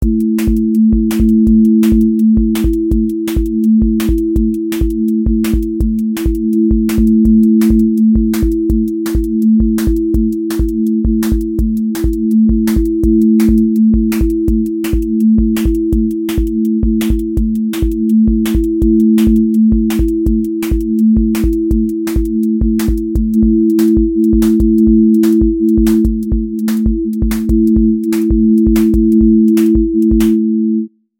QA probe drum-and-bass break pressure with amen-led switchups, sub ownership, and rolling atmosphere
• voice_kick_808
• voice_snare_boom_bap
• voice_sub_pulse
• fx_space_haze_light